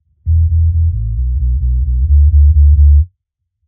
Index of /musicradar/dub-designer-samples/130bpm/Bass
DD_JBass_130_E.wav